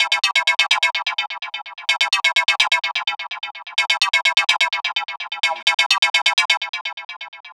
Lead 127-BPM E.wav